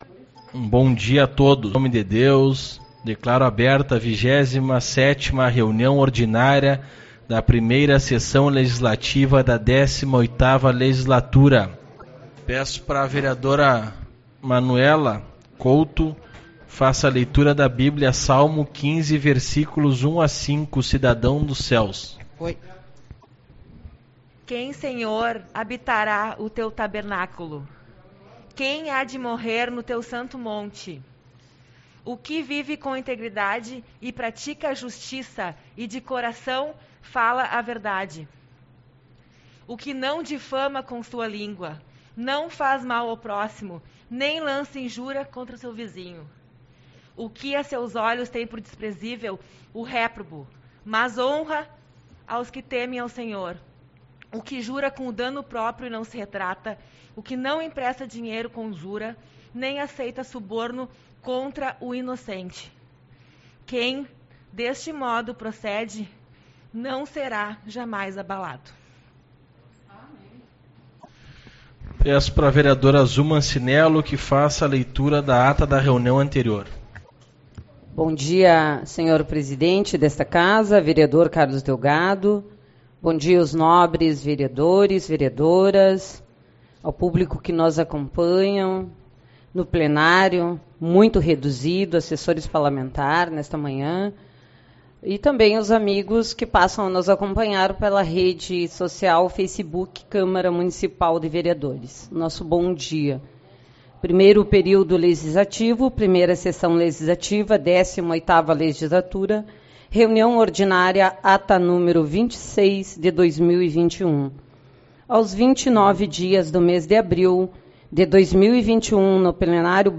04/05 - Reunião Ordinária